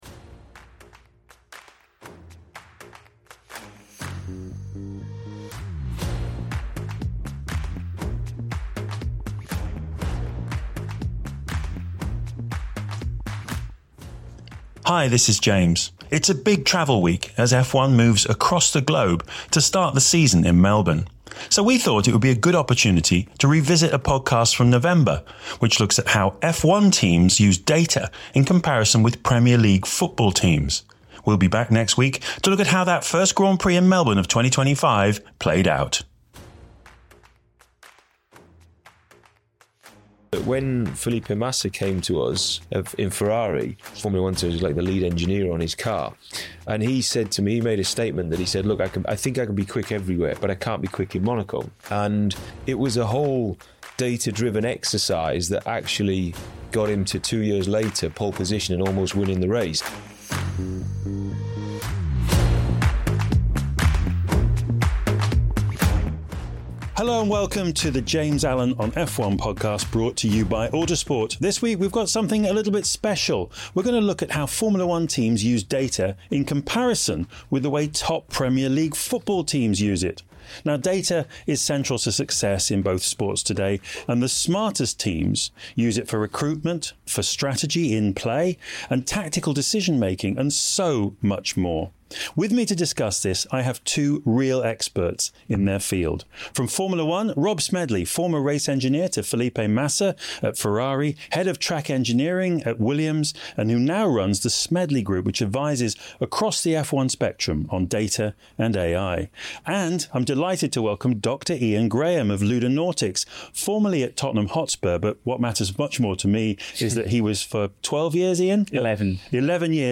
Three-time BAFTA award-winning F1 commentator James Allen returns to the broadcast mic with a thoughtful and engaging new podcast, looking at the human side of the sport. Every episode will feature an insightful 20-minute interview with a prominent figure from inside and around the sport focusing on themes beyond the everyday news cycle.